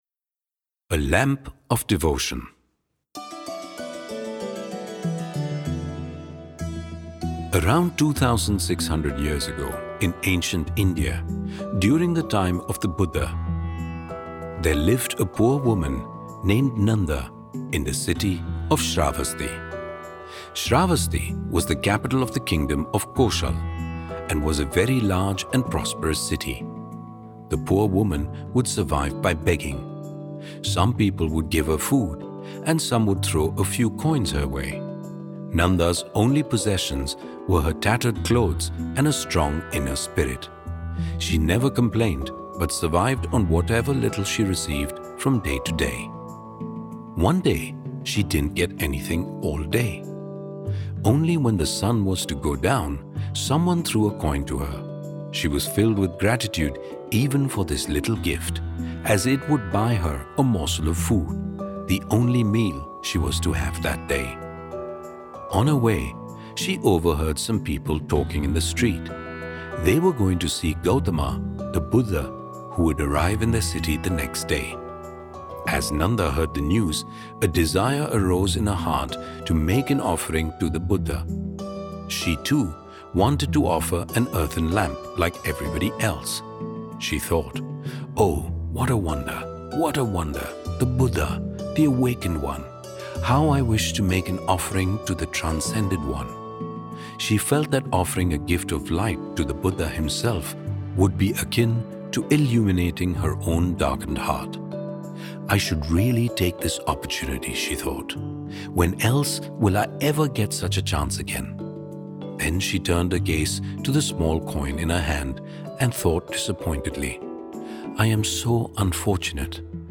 A Lamp of Devotion (Audio Story)